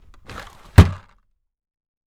DRAWER2 CL-S.WAV